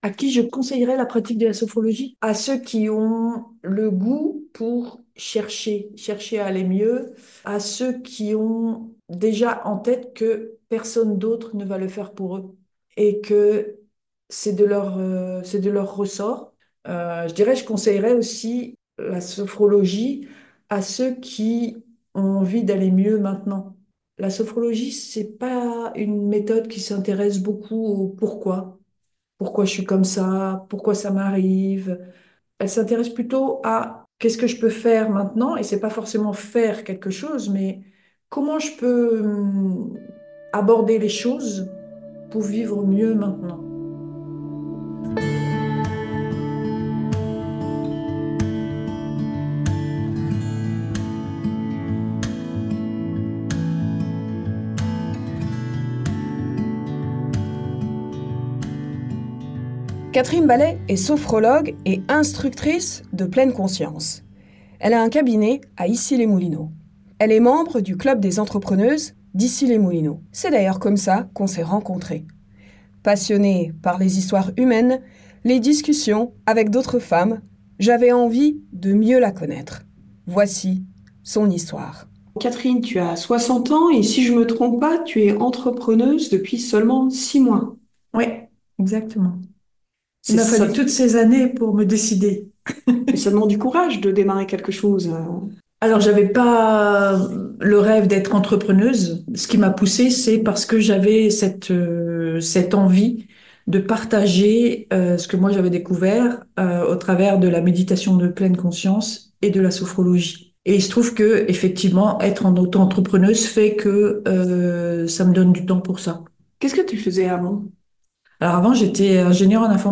Mon parcours avec la Pleine Conscience et la Sophrologie. Entretien